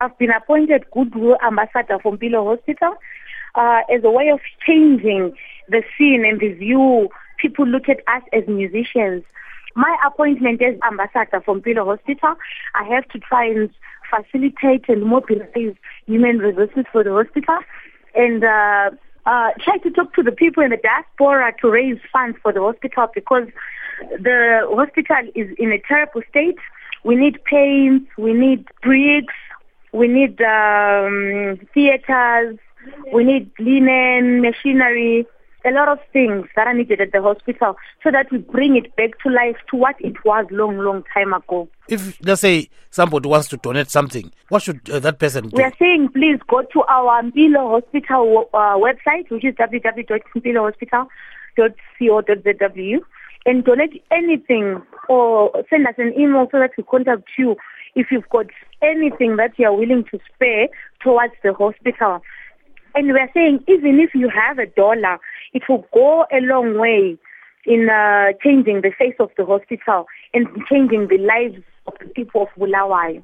Interview With Sandra Ndebele